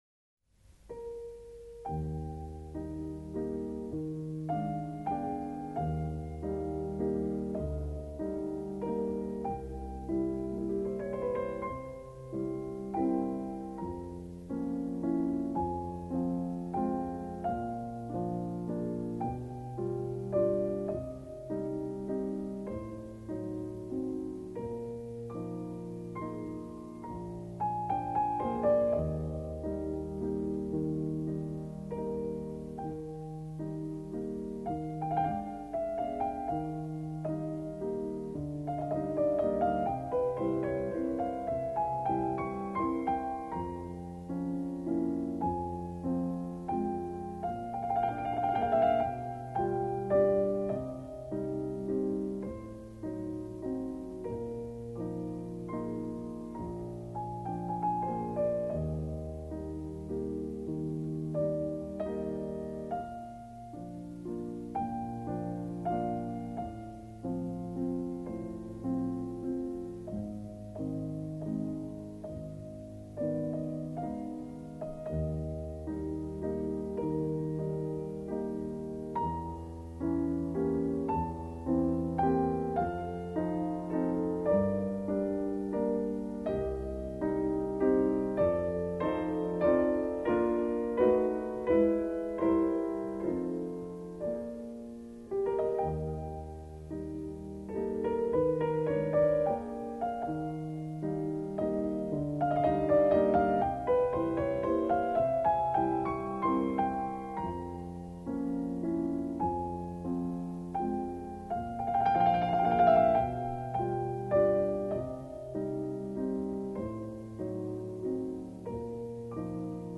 Жанр: Classical